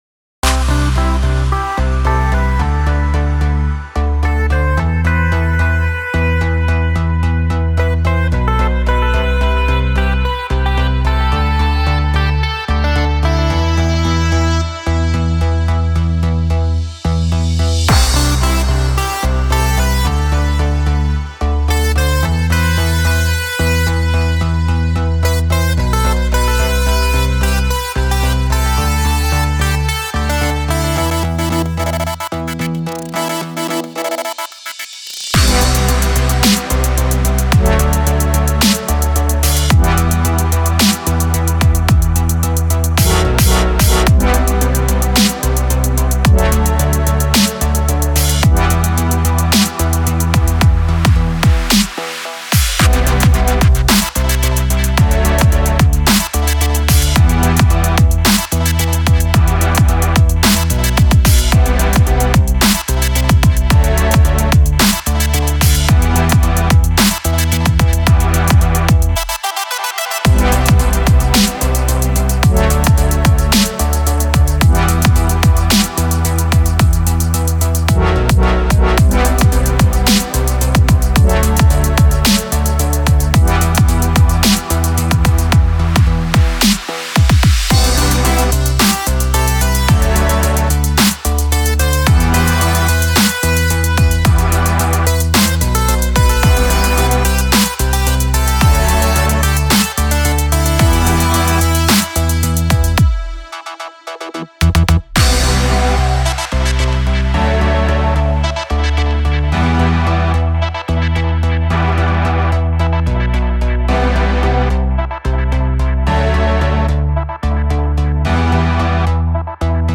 我使用了几种不同的VST合成器来制作这些和弦样本（Spire，Serum，Massive，Diva，Dune2）以及我的硬件设备：Novation UltraNova，Behringer DeepMind12，Novation Circuit。
这些样本可用于制作流派，例如：未来的低音，颤音，冷颤陷阱，颤音，环境低音，抽象电子乐，降速等。